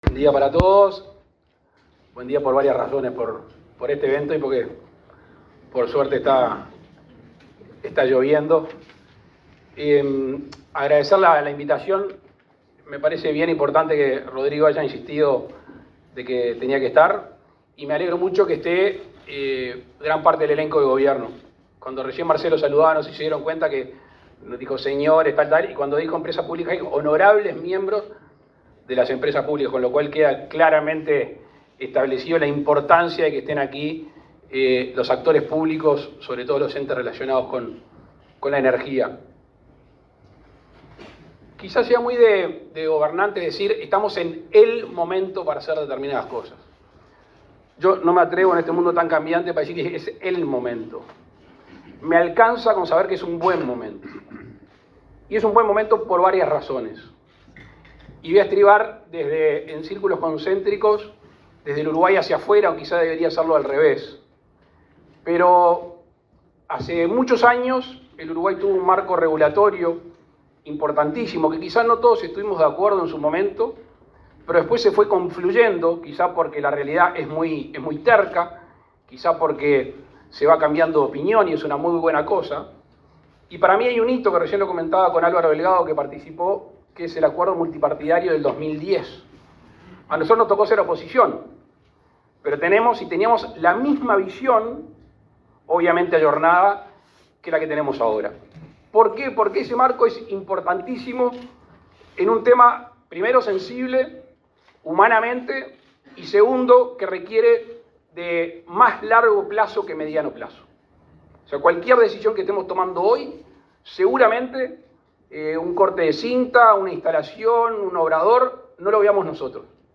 Palabras del presidente Luis Lacalle Pou
Palabras del presidente Luis Lacalle Pou 26/07/2023 Compartir Facebook X Copiar enlace WhatsApp LinkedIn El presidente de la República, Luis Lacalle Pou, participó en la apertura del IX Congreso Latam Renovables, que se realiza en el Laboratorio Tecnológico del Uruguay los días 26 y 27 de este mes.